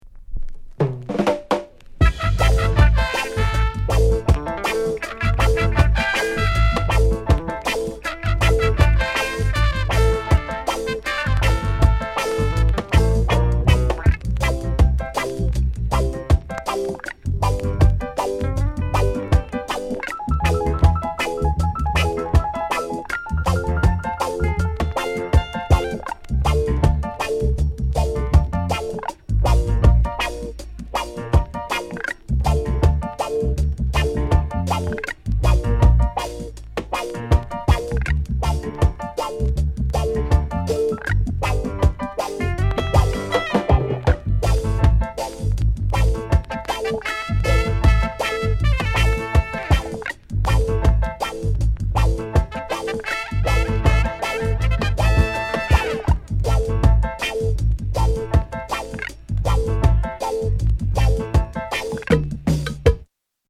RHYTHM TRACK
RARE FUNKY REGGAE